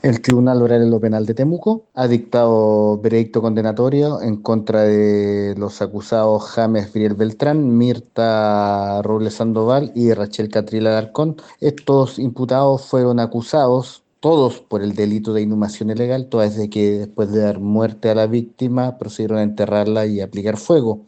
Lo anterior, lo confirmó a Radio Bío Bío el fiscal del Ministerio Público, Miguel Ángel Velásquez, quien sostuvo la acusación en la causa.